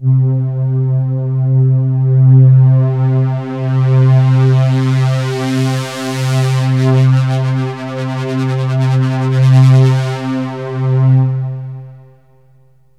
AMBIENT ATMOSPHERES-3 0003.wav